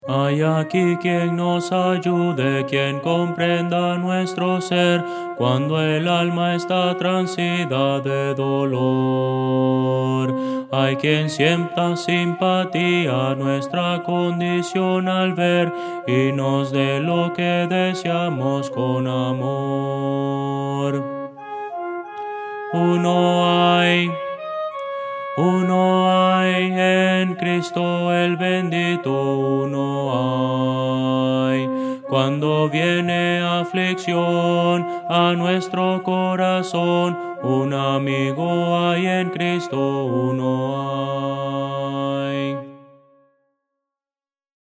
Voces para coro
Audio: MIDI